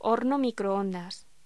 Locución: Horno microondas
voz